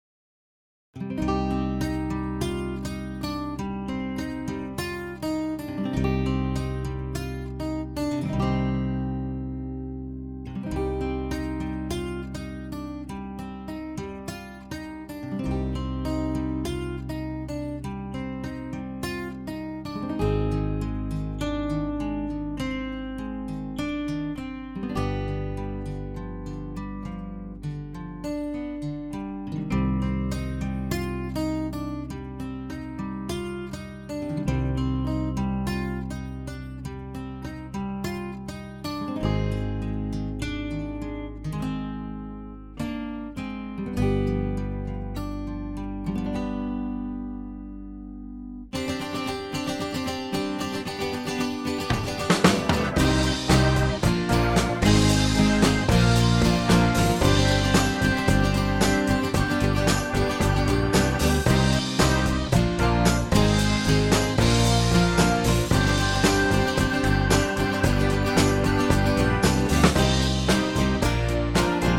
key - Cm - vocal range - G to G
Hammond organ takes the backing vocal lines.